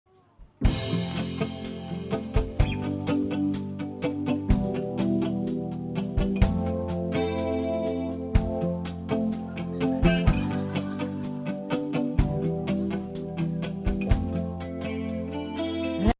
（音源；当日のライブ演奏より）